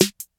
• 2000s Sparkly Rap Snare Sound G Key 423.wav
Royality free snare sample tuned to the G note. Loudest frequency: 3299Hz